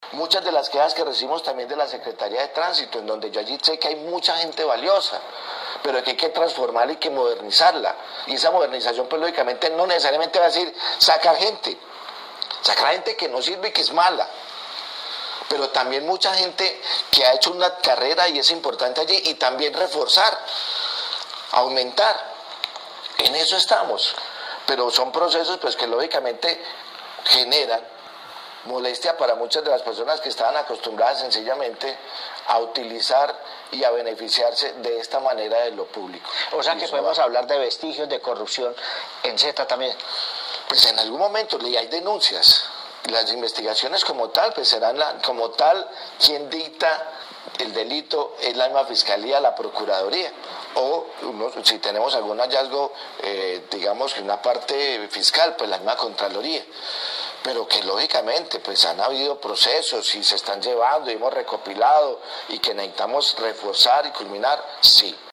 En entrevista a Periodismo Investigativo el 28 de agosto del presente año, el alcalde de Armenia José Manuel Ríos Morales, confirmó sobre el proceso penal que la fiscalía adelantaba por presunta corrupción en Setta.